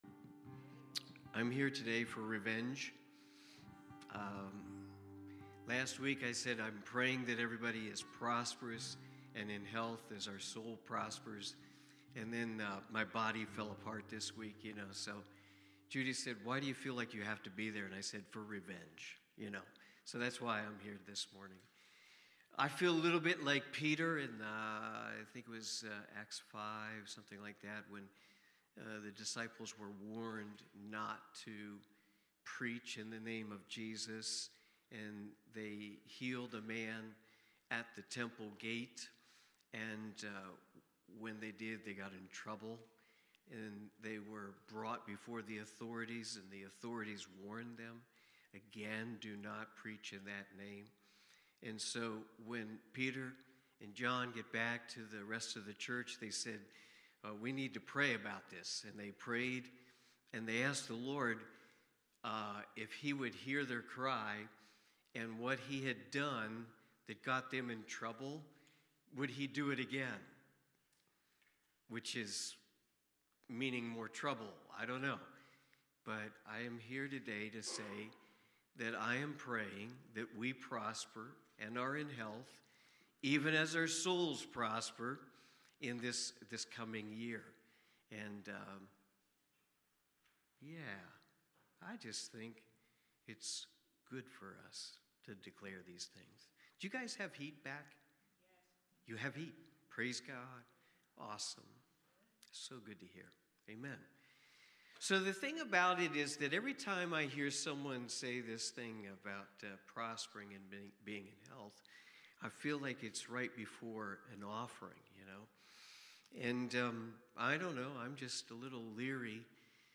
3 John Watch Listen Save Cornerstone Fellowship Sunday morning service, livestreamed from Wormleysburg, PA.